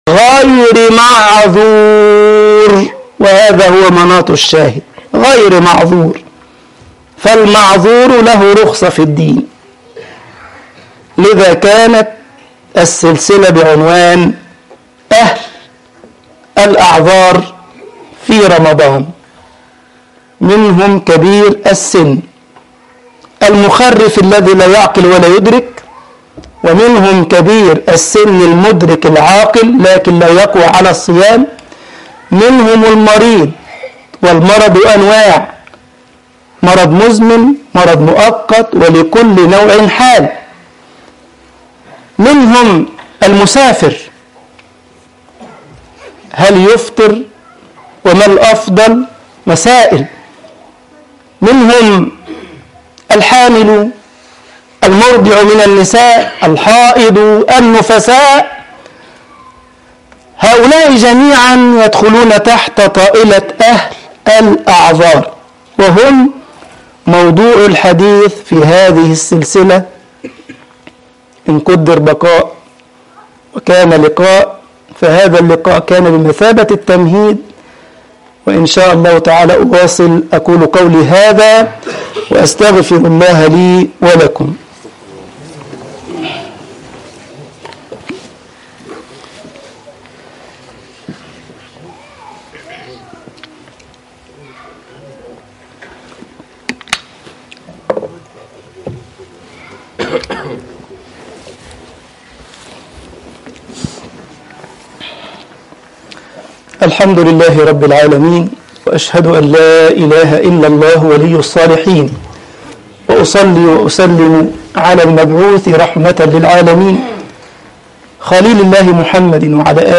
أهل الأعذار في رمضان 1 - مقطع من الخطبة